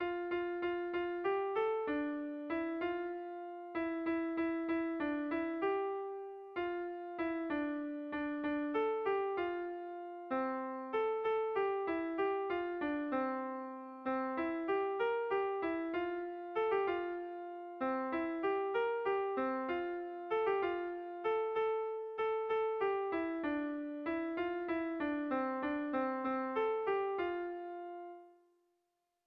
Gabonetakoa
Doinu polita.
Zortziko txikia (hg) / Lau puntuko txikia (ip)
ABDE